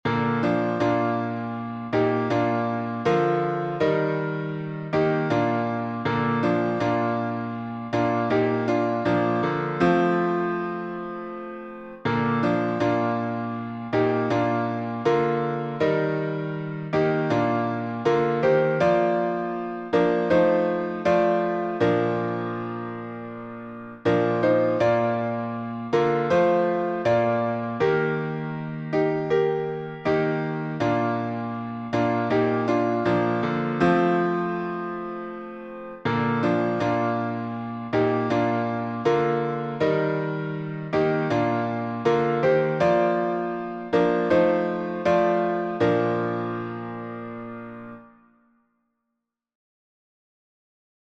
19th Century American camp meeting tune Key signature: B flat major (2 flats) Time signature: 4/4